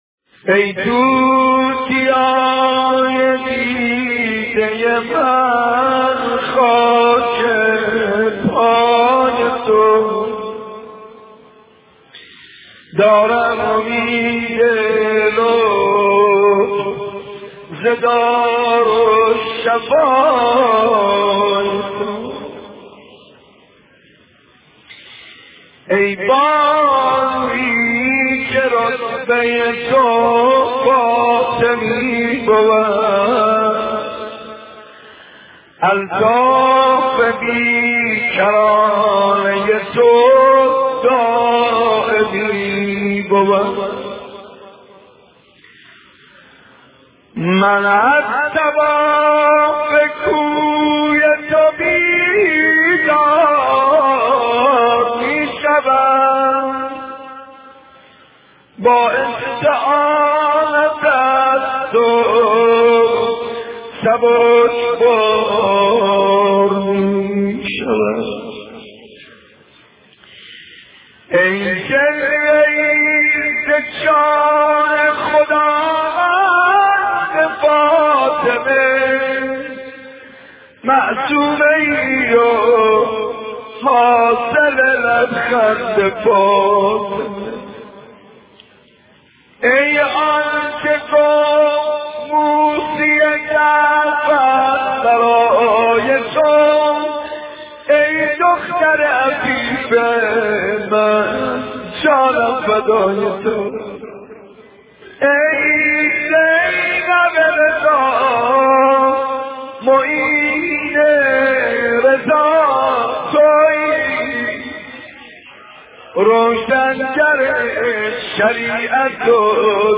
روضه حضرت معصومه
مداحی شهادت حضرت معصومه